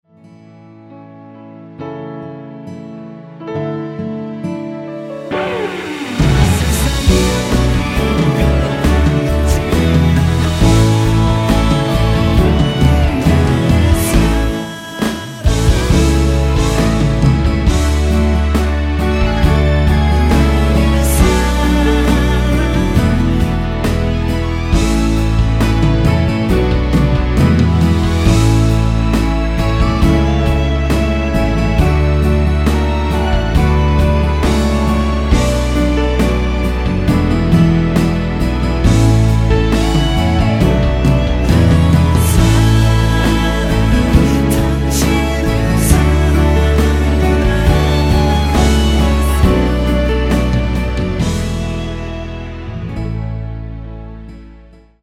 원키 코러스 포함된 MR 입니다.(미리듣기 참조)
앞부분30초, 뒷부분30초씩 편집해서 올려 드리고 있습니다.